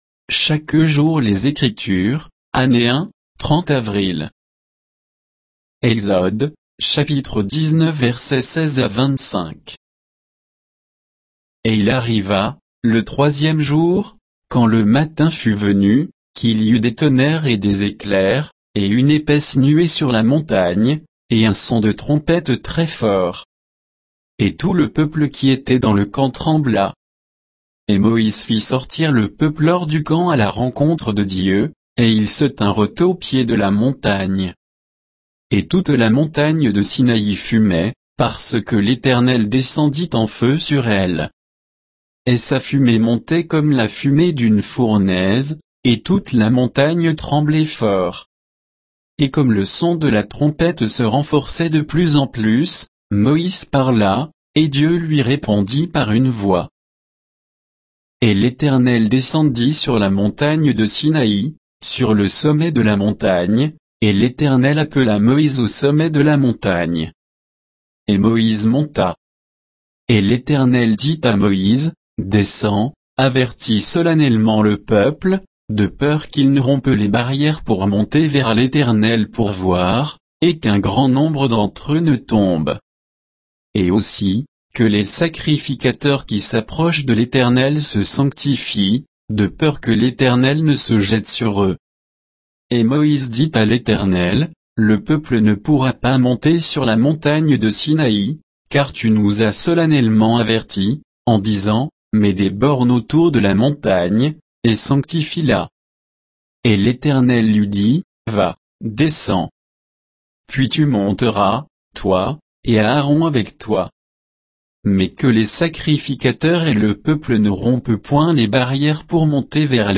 Méditation quoditienne de Chaque jour les Écritures sur Exode 19